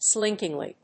アクセント・音節slínk・ing・ly